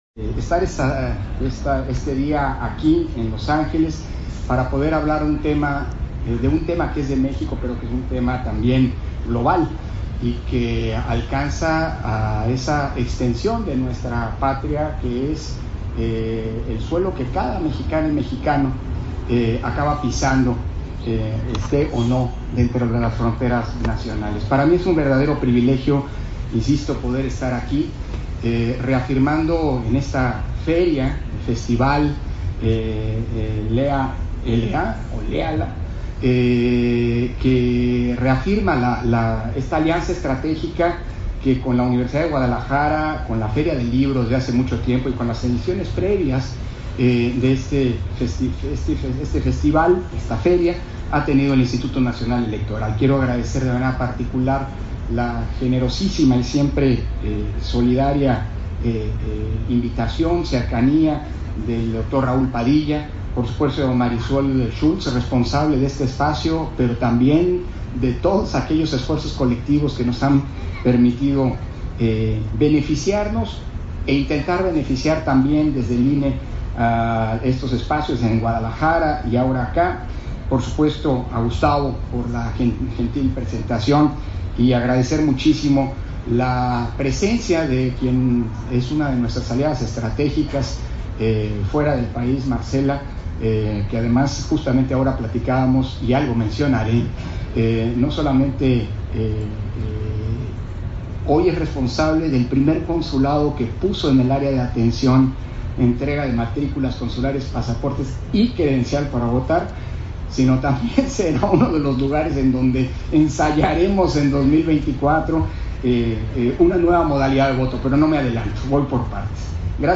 250822_AUDIO_CONFERENCIA-MAGISTRAL-CONSEJERO-PDTE.-CÓRDOVA-FERIA-DEL-LIBRO-LÉALA
Conferencia de Lorenzo Córdova, titulada, México está donde tu estás, en el marco de la feria del libro en español y festival literario LÉALA